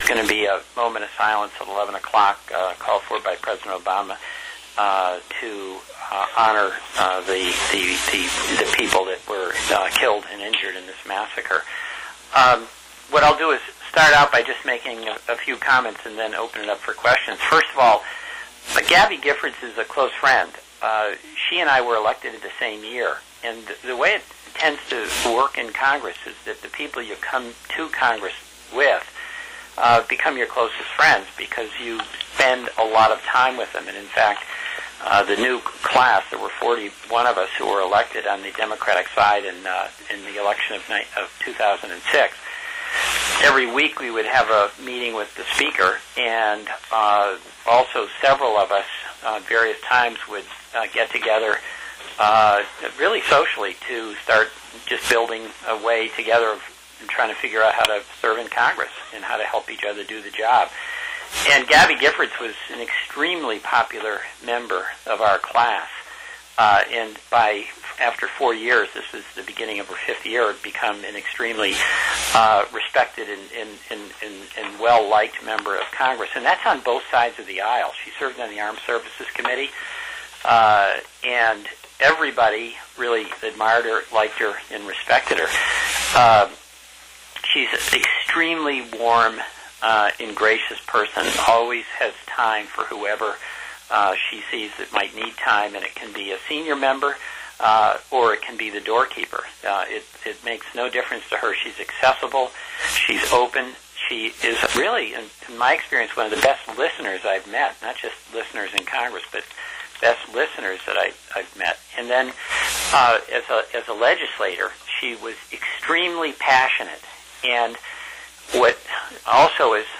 Rep. Peter Welch, D-Vt., held a press conference at 10:15 a.m. about the shooting on Sunday of Rep. Gabby Giffords, D-Ariz., who was shot in the head at close range during a meeting with voters in Tuscon.